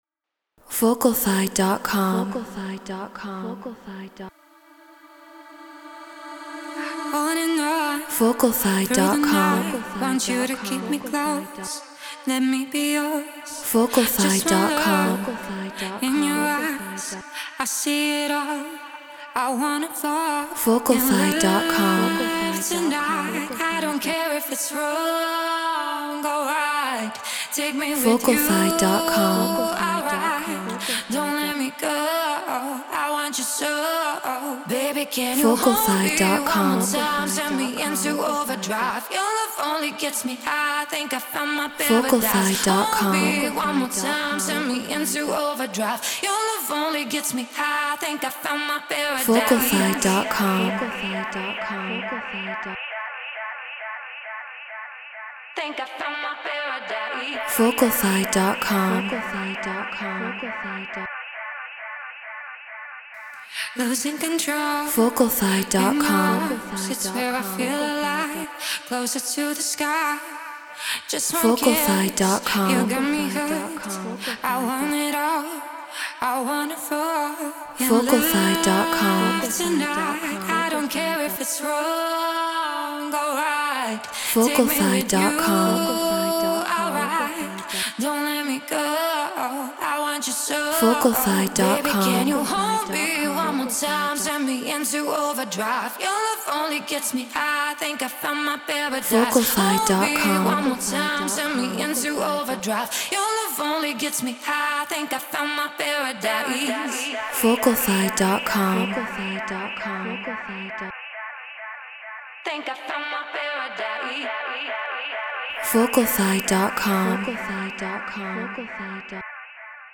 EDM 135 BPM Amin